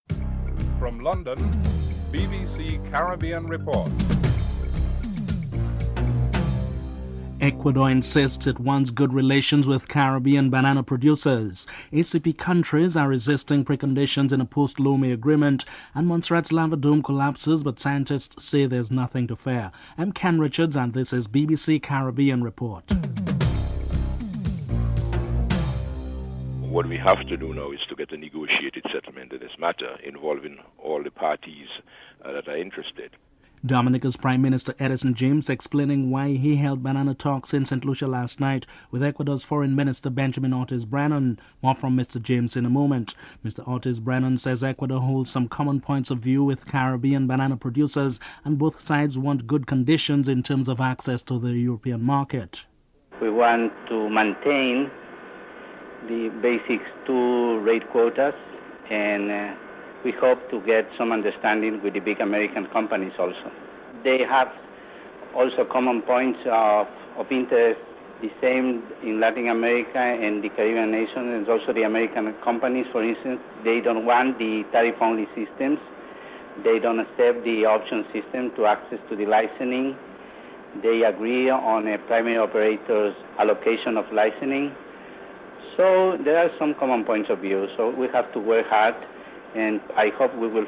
dc.contributor.authorJames, Edison (interviewee)
dc.contributor.authorOrtiz, Benjamin (interviewee)
dc.contributor.authorGreenidge, Carl (interviewee)
dc.description.tableofcontents2. Dominica Prime Minister Edison James is in dialogue with Ecuador Foreign Minister Benjamin Ortiz on the banana industry.
dc.typeRecording, oralen_US